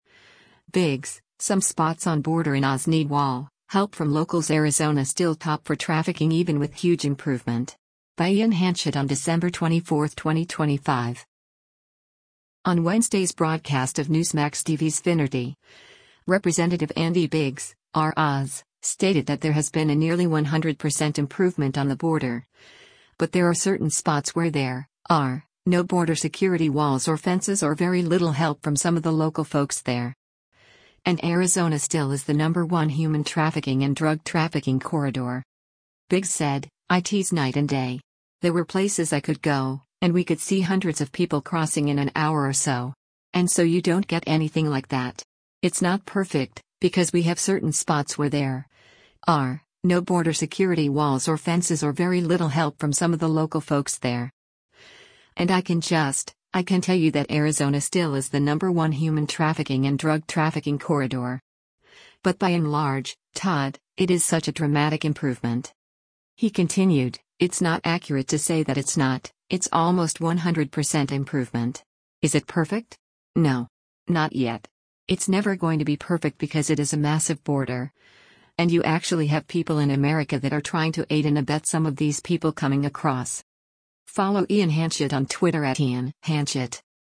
On Wednesday’s broadcast of Newsmax TV’s “Finnerty,” Rep. Andy Biggs (R-AZ) stated that there has been a nearly 100% improvement on the border, but there are “certain spots where there [are] no border security walls or fences or very little help from some of the local folks there.”